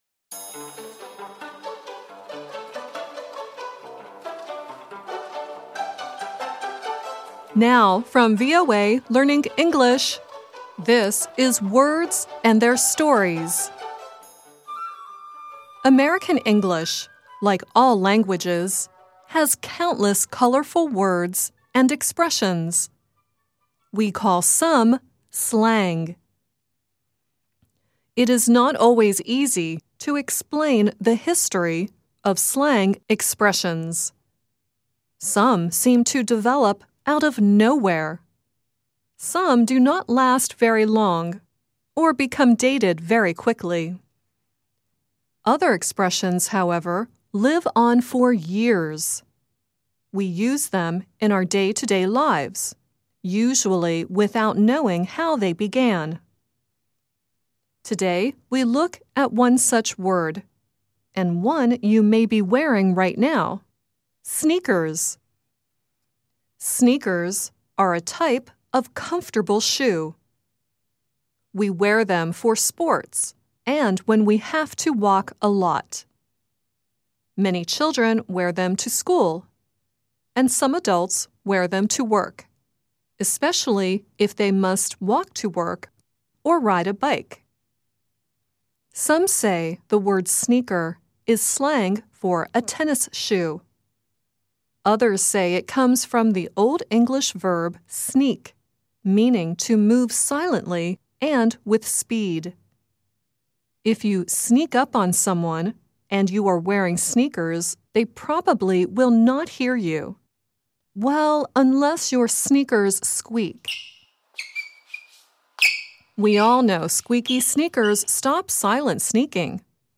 At the end of the show, Vanessa Hudgens sings "Sneakernight."